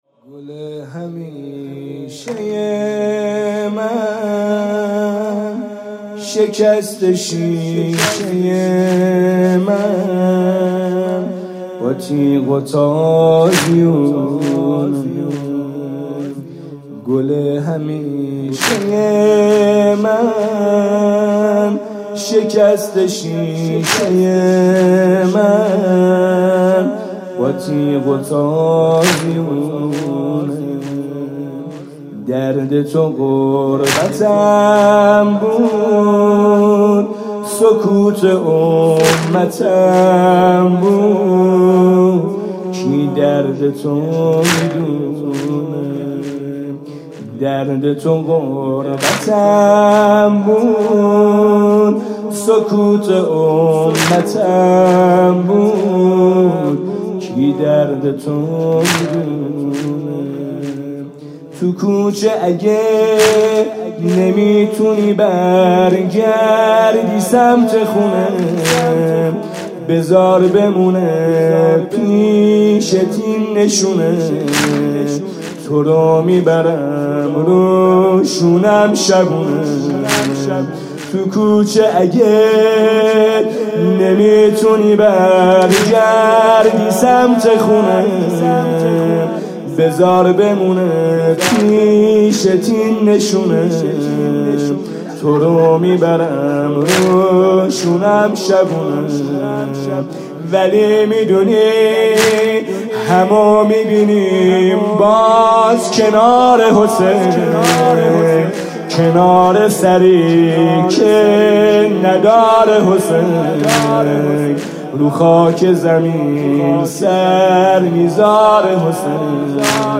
شب پنجم فاطمیه اول ۱۴۰۴ | هیأت میثاق با شهدا
music-icon تک